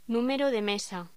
Locución: Número de mesa
voz
Sonidos: Hostelería